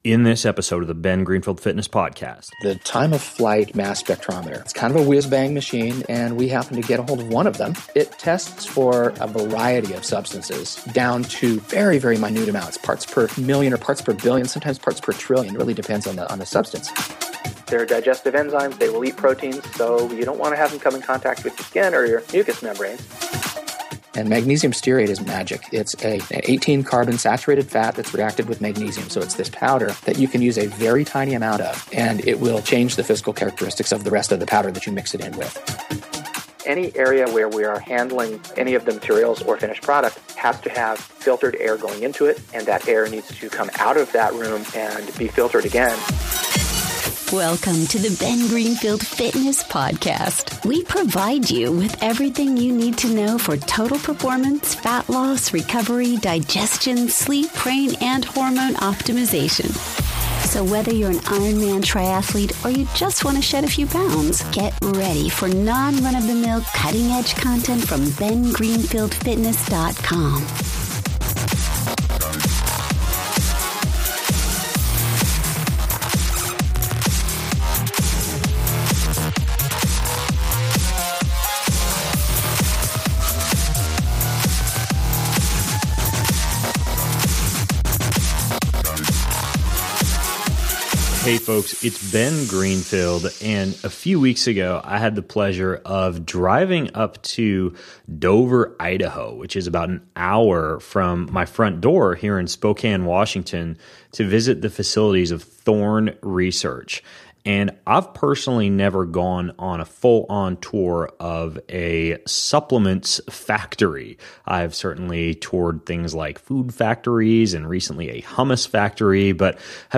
Behind The Scenes Of How A Supplement Is Made: An Insider Interview.